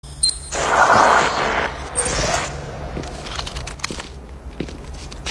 • BEEPS AND CRACKLES ELECTRICAL.wav
BEEPS_AND_CRACKLES_ELECTRICAL_DRi.wav